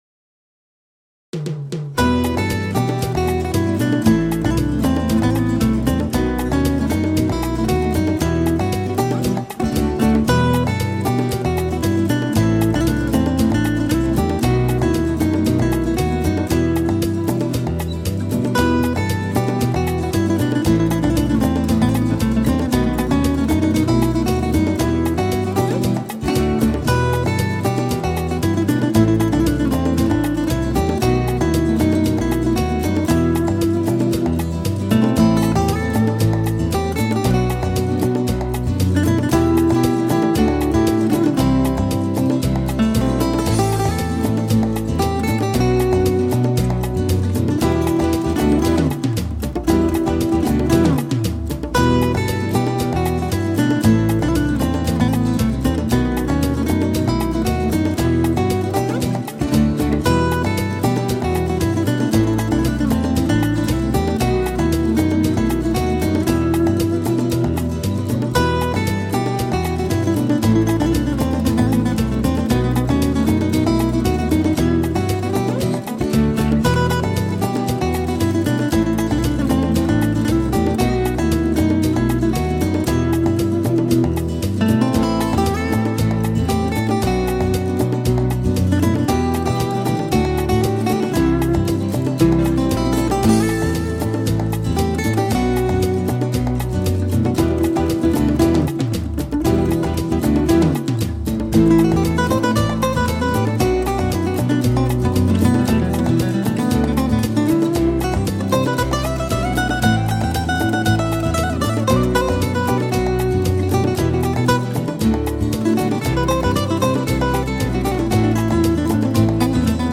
Latin Guitar
Acoustic Guitar